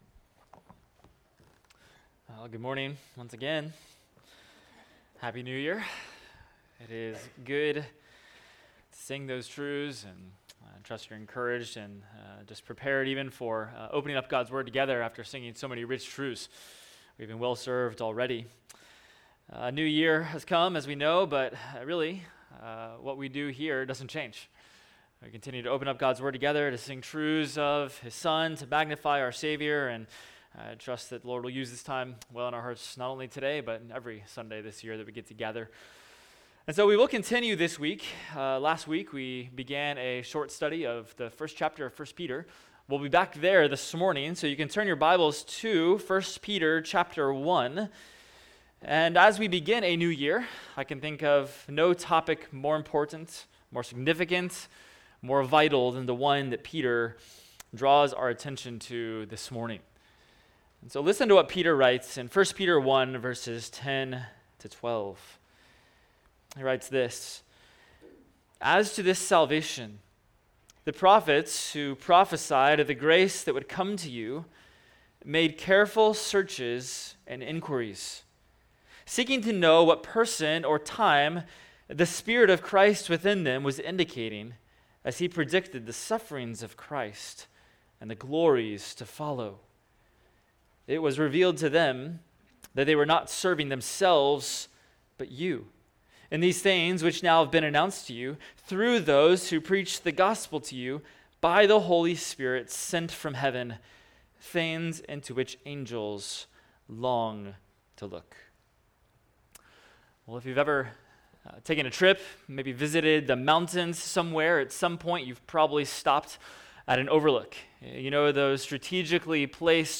Expository Preaching from First Peter – 1 Peter 1:10-12 Marveling at Our Glorious Salvation